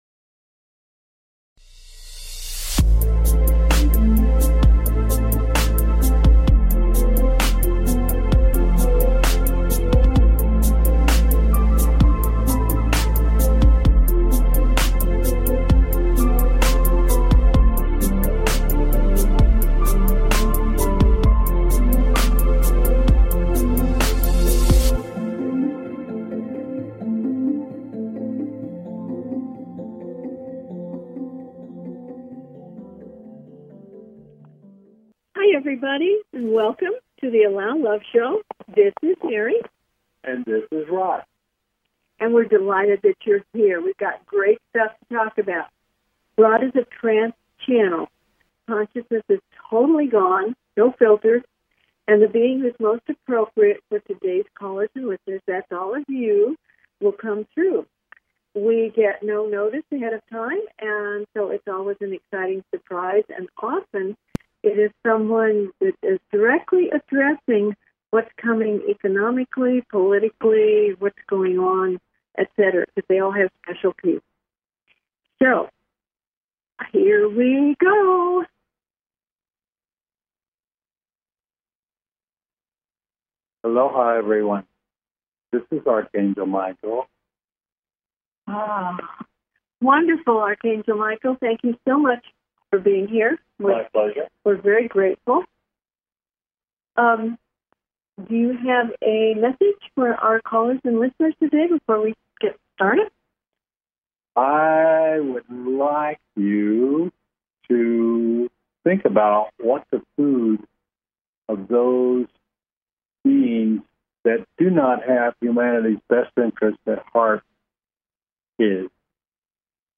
Talk Show Episode
Their purpose is to provide answers to callers’ questions and to facilitate advice as callers request.